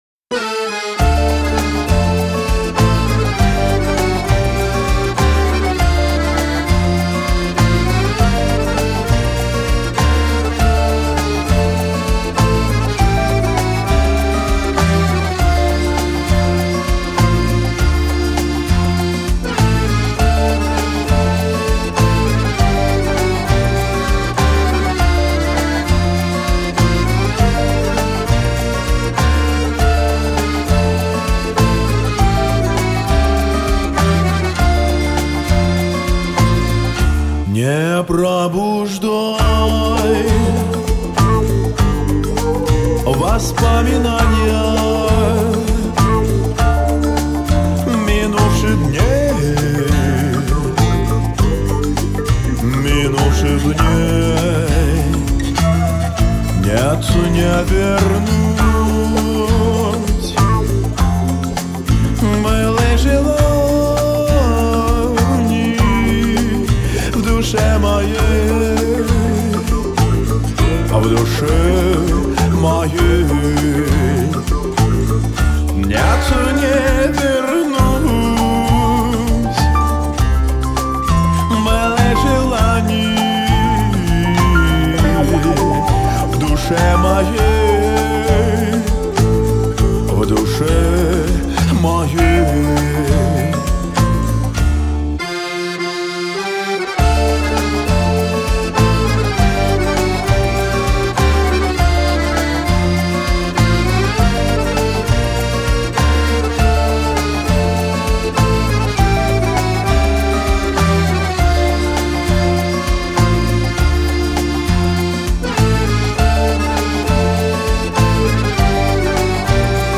Русский романс…